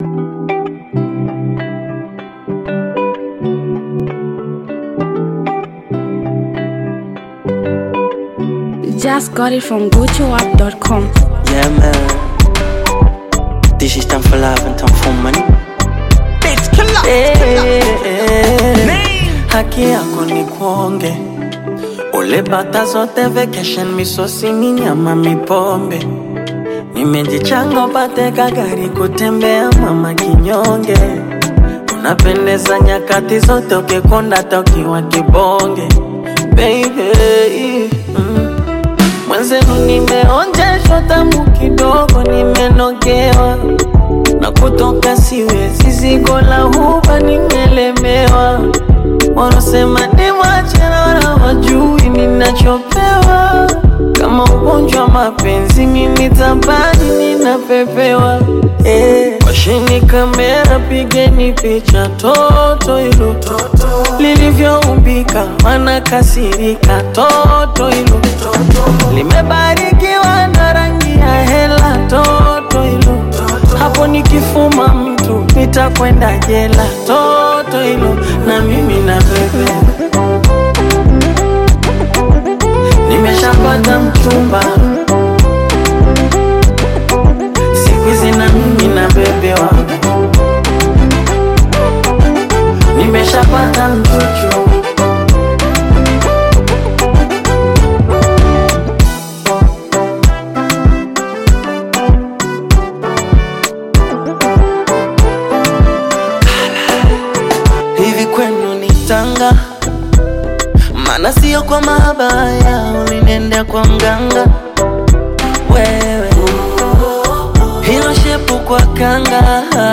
Tanzanian bongo music
buzzing street anthem record